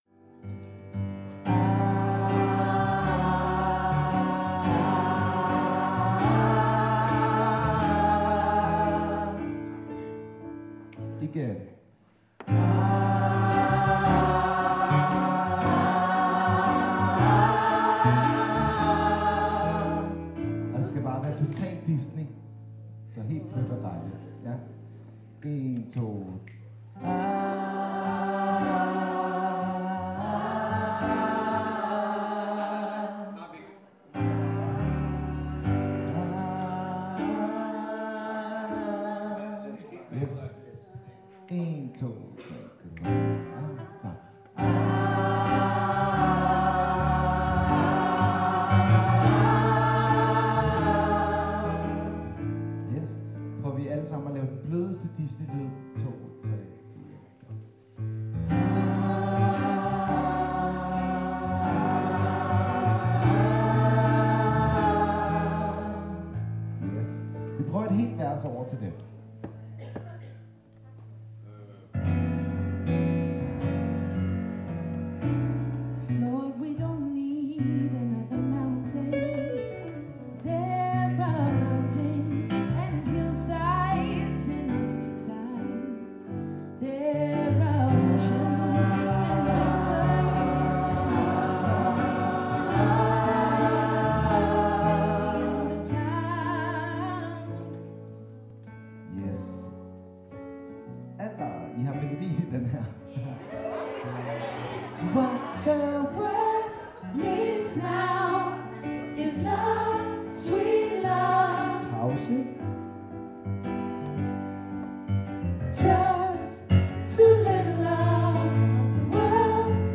what_the_wold_needs_alt.mp3